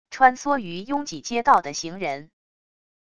穿梭于拥挤街道的行人wav音频